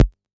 RDM_TapeB_SR88-Perc.wav